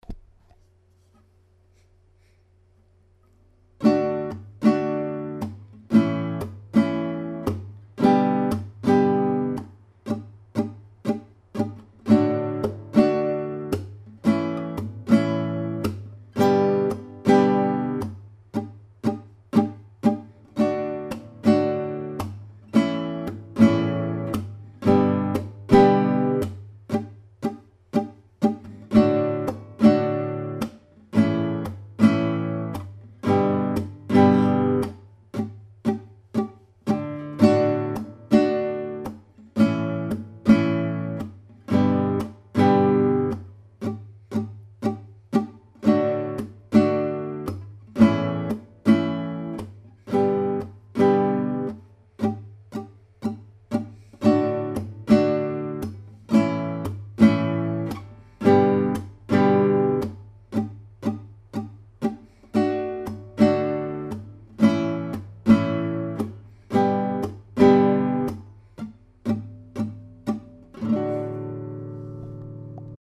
Ecoute l'accompagnement à la guitare Je ne sais pas :
Je ne sais Accompagnement.mp3